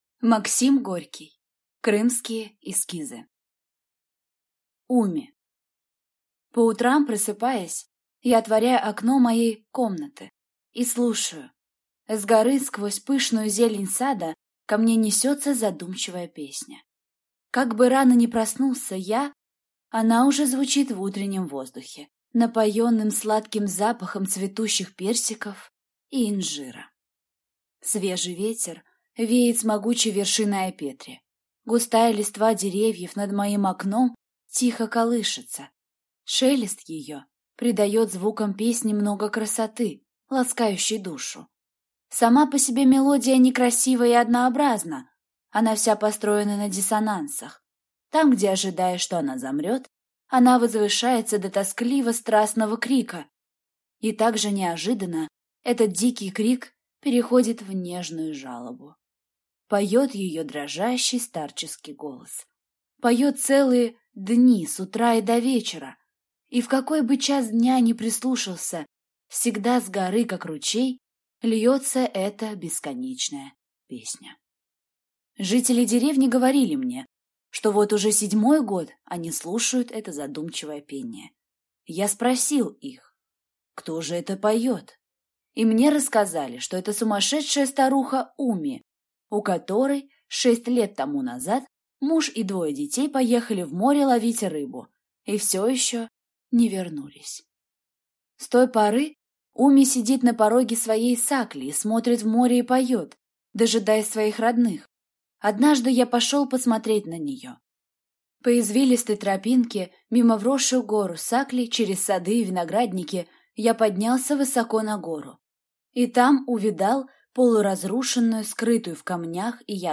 Аудиокнига Крымские эскизы | Библиотека аудиокниг